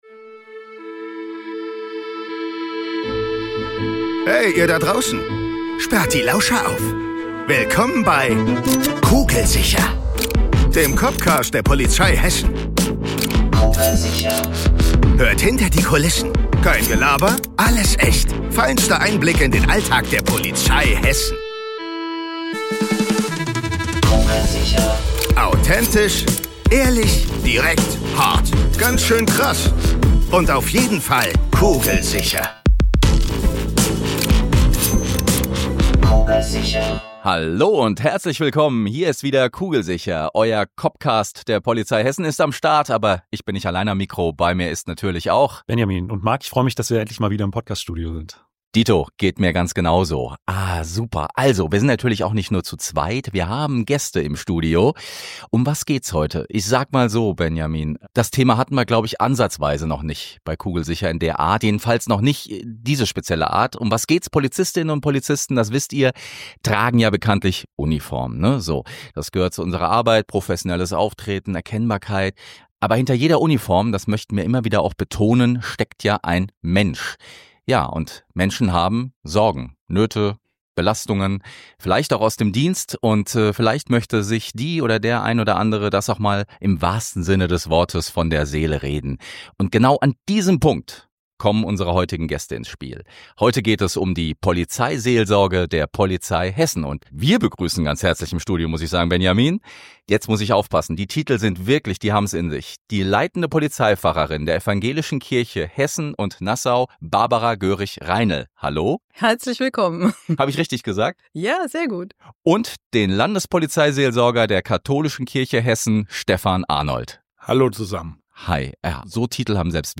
Bei uns dreht sich alles um den echten Polizeialltag, mit echten Cops am Mikro. Deswegen ist das hier auch kein stinknormaler Podcast, sondern ein COPCAST!